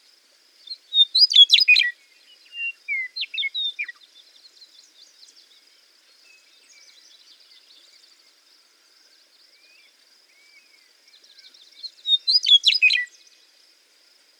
Passerines (Songbirds)
Western Meadow Lark